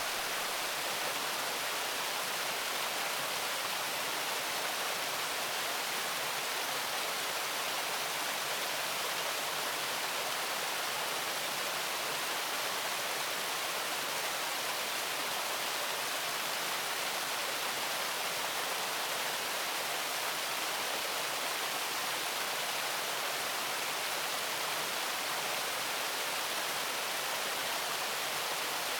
Watterfall_SFX.ogg